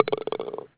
BallHole.wav